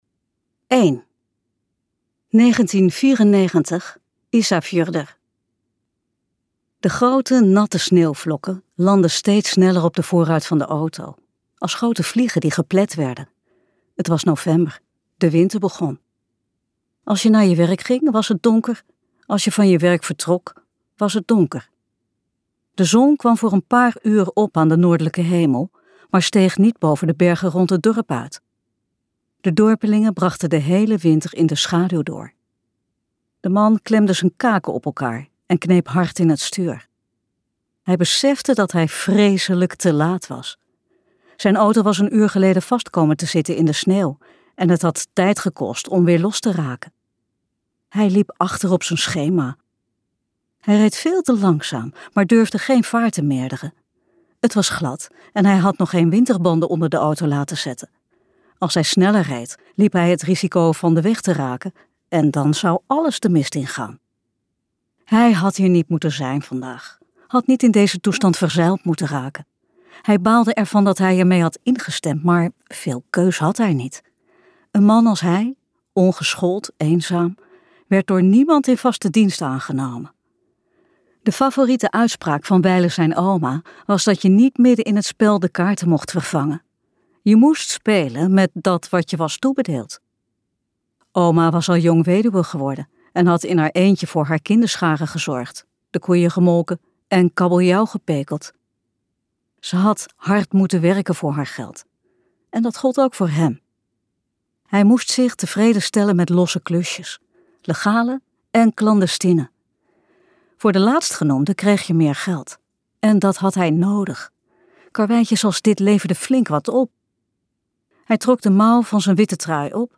Ambo|Anthos uitgevers - Rosa en bjork luisterboek